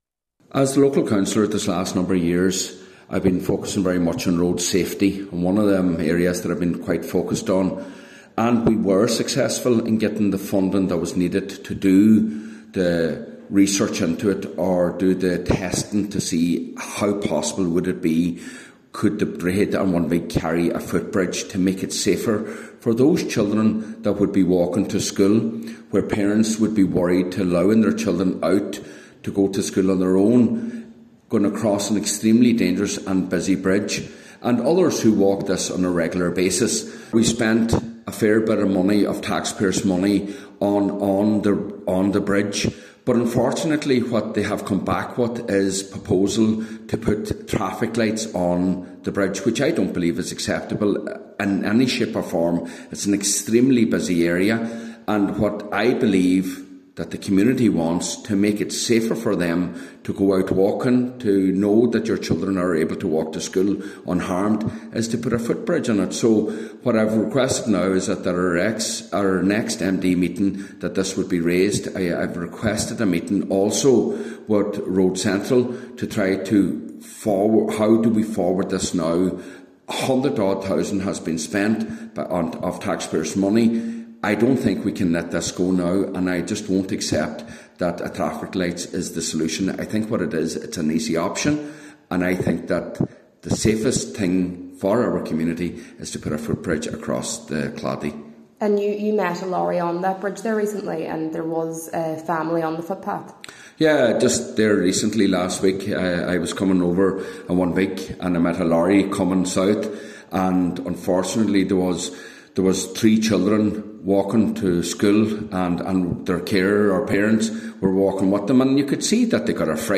But, Councillor Micheal Choilm MacGiolla Easbuig says that is nowhere near enough to address the serious safety concerns: